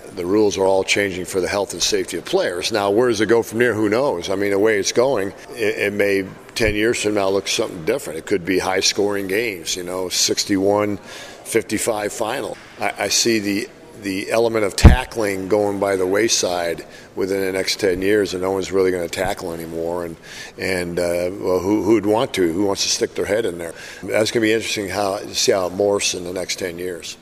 LONG WAS THE FEATURED SPEAKER MONDAY AT THE SIOUX CITY ROTARY CLUB.